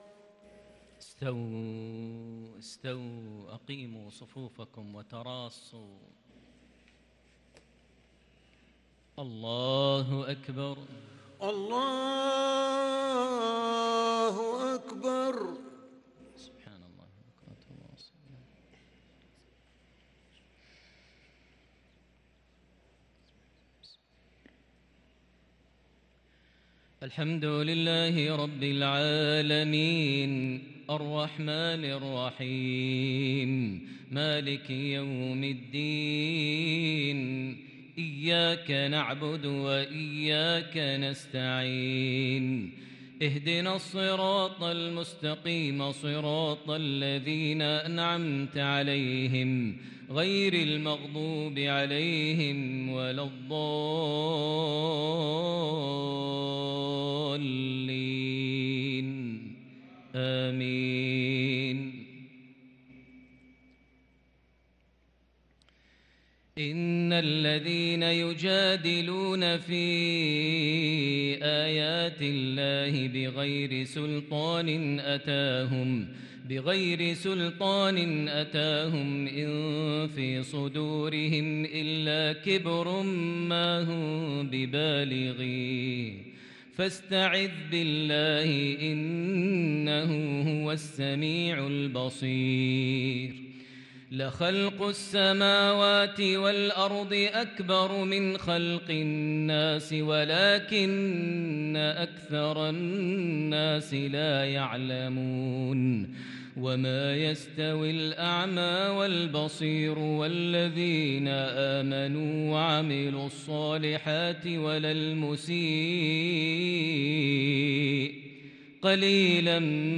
صلاة العشاء للقارئ ماهر المعيقلي 28 ربيع الآخر 1444 هـ
تِلَاوَات الْحَرَمَيْن .